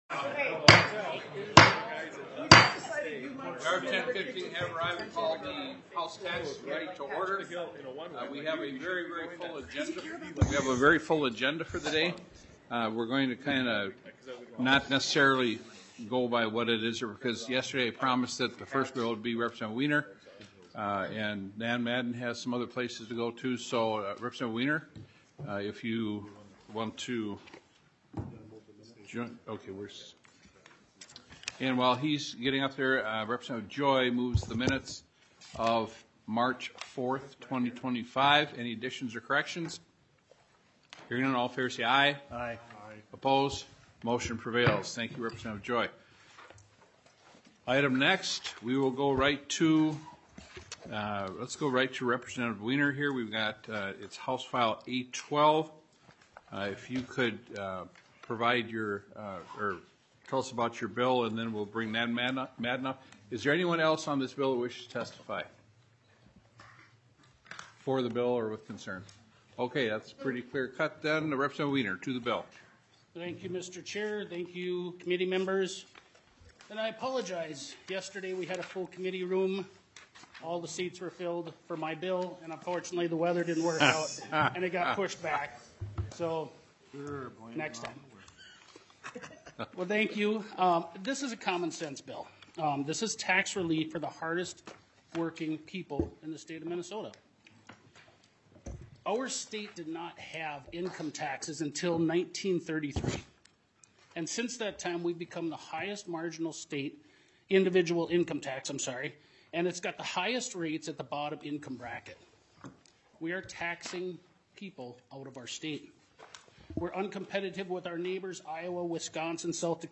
Taxes TENTH MEETING - Minnesota House of Representatives